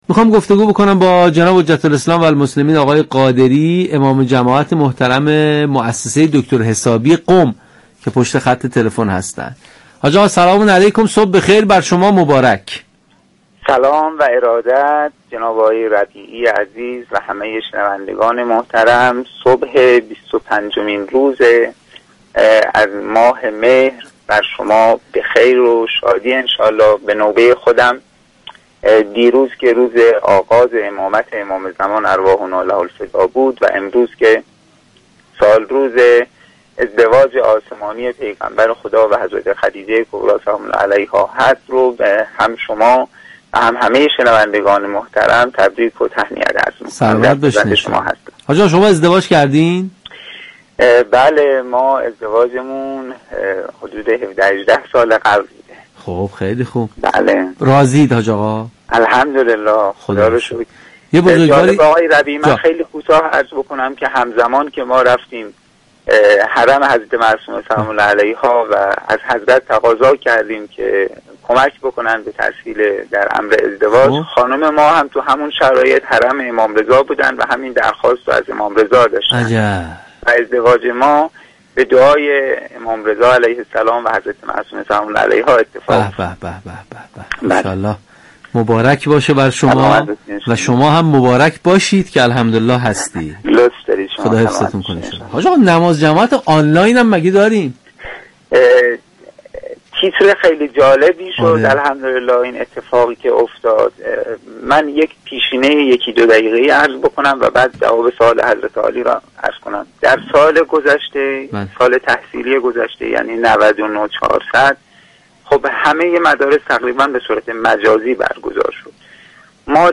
از طریق موج اف.ام ردیف 100 مگاهرتز شنونده ما باشید.